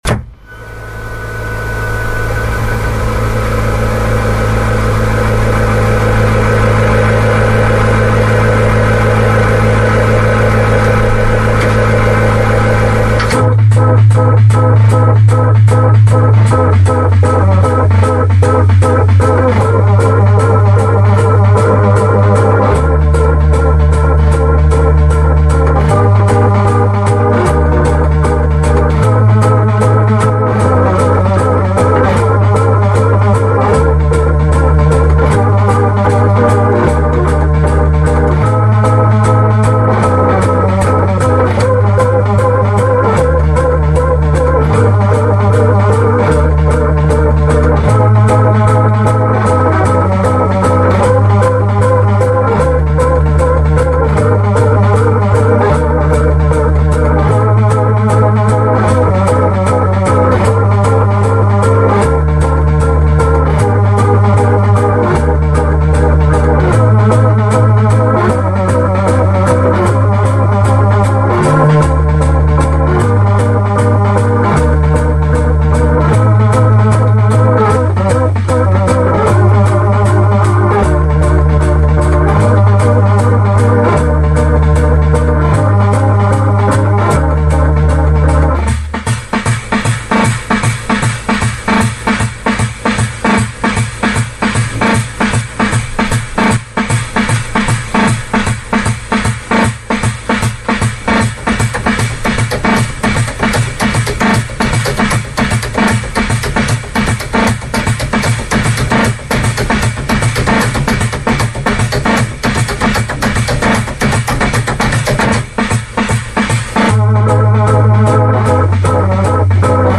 Wurlitzer Funmaker Super Sprite (Modell 400)
Der Musiker kann sich von fünf bombastischen Rhythmen begleiten lassen: Waltz, Latin, Swing, Rock und March.
wurlitzer.mp3